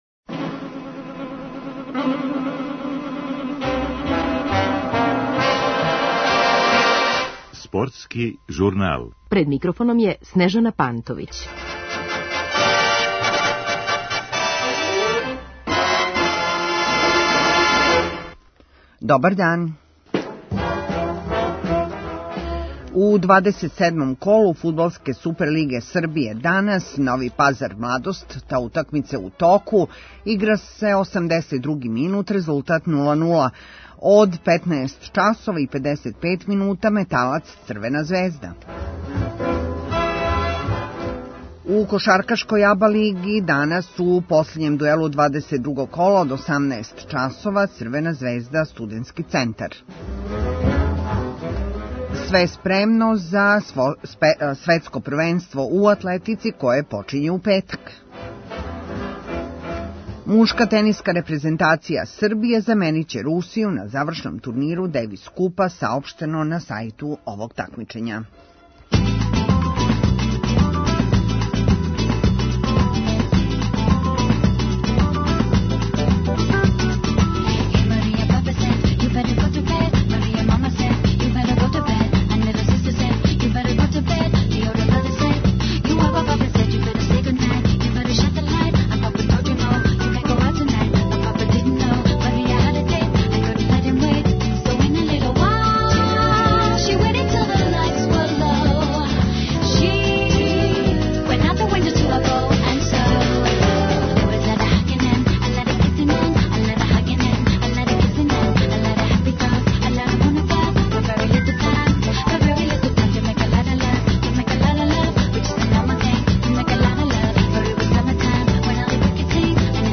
Пратимо утакмијце 27-мог кола фудбалске Суперлиге Србије Нови Пазар - Младост и Металац – Црвена звезда. Гост, стручни коментаторје фудбалски стручњак